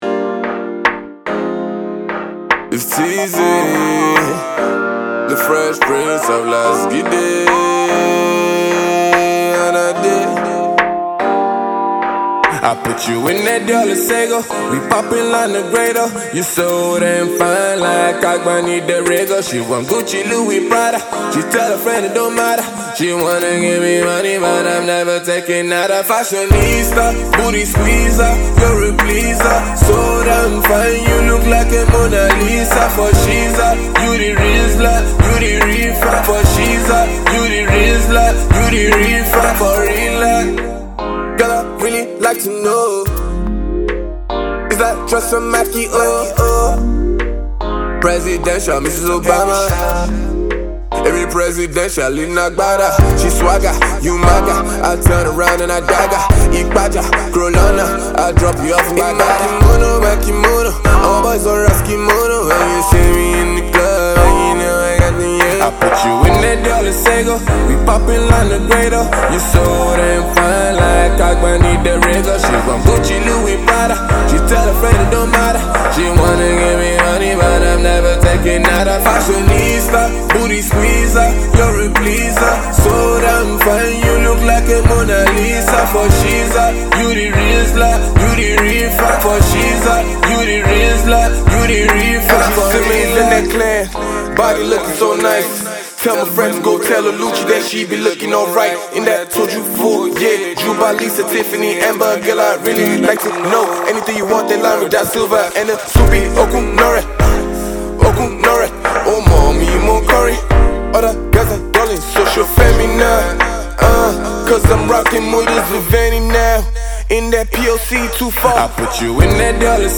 alternative hit
gives us ‘alter vibes’
The track definitely has a cool and catchy vibe to it!